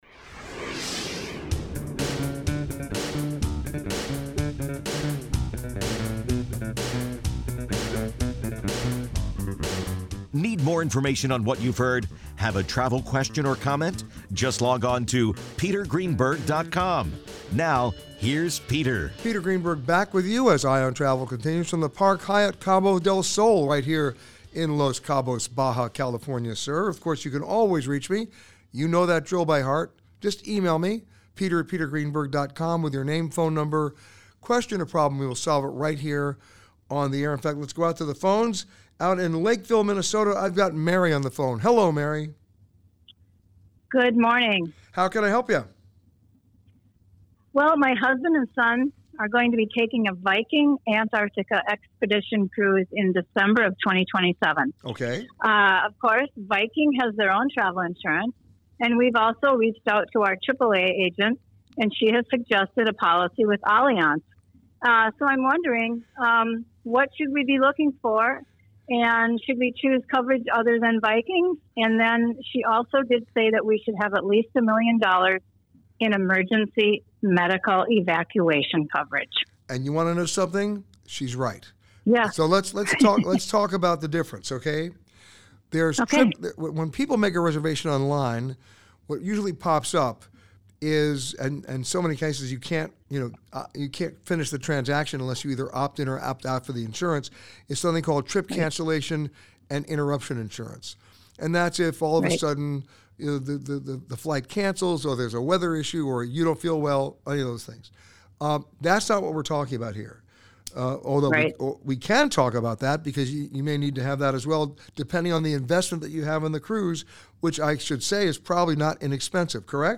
This week, Peter answered your questions from The Park Hyatt Cabo del Sol in Los Cabos, Baja California Sur.